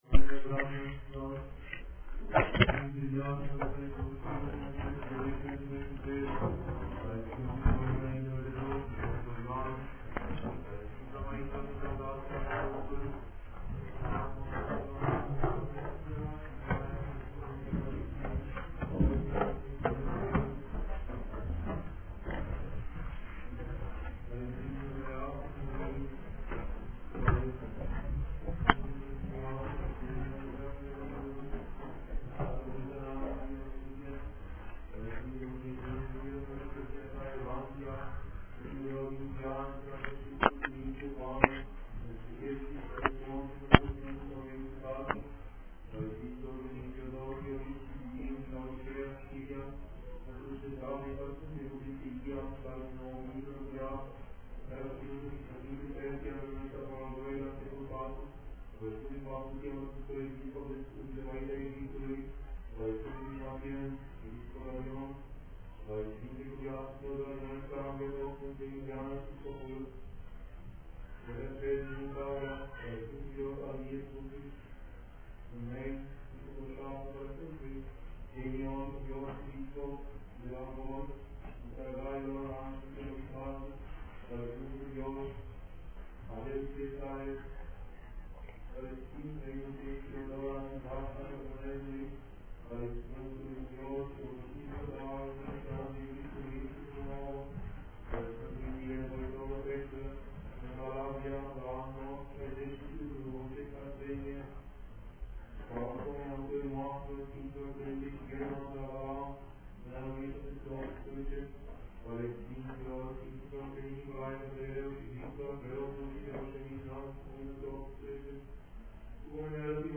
Predica la Sfanta Liturghie